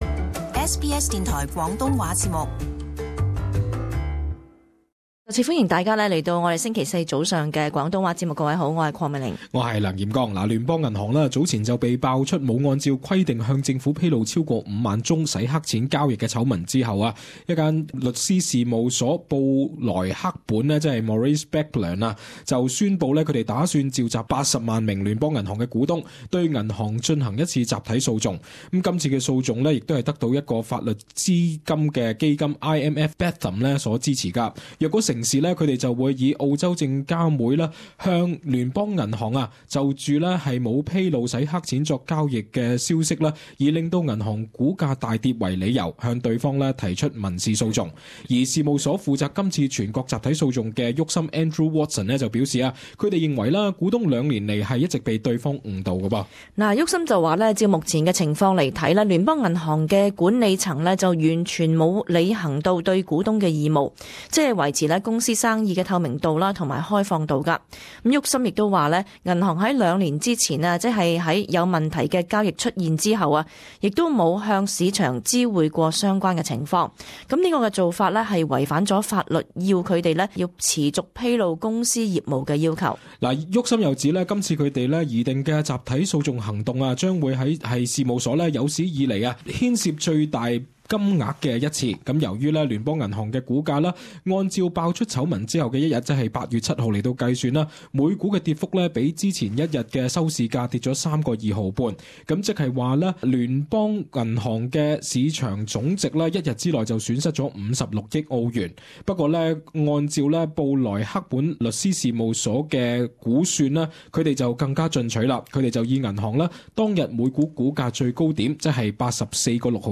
【時事報導】聯邦銀行有機會面臨股東集體訴訟